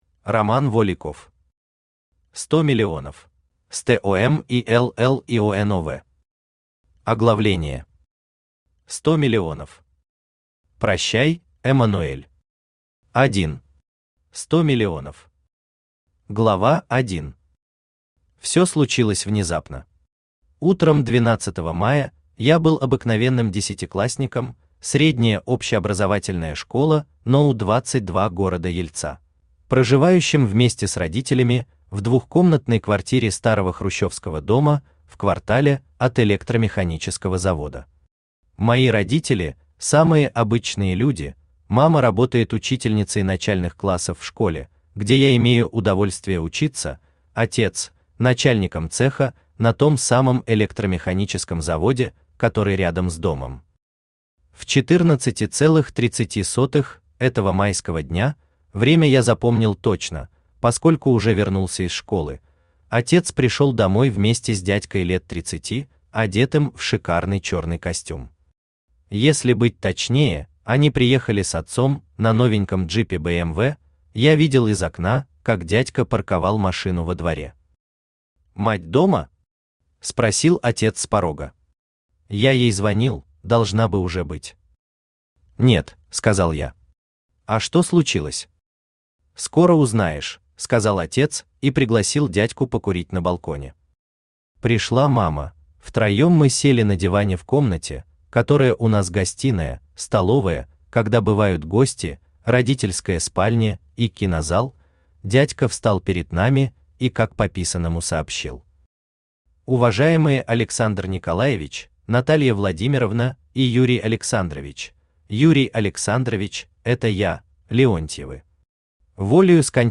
Аудиокнига Сто миллионов | Библиотека аудиокниг
Aудиокнига Сто миллионов Автор Роман Воликов Читает аудиокнигу Авточтец ЛитРес.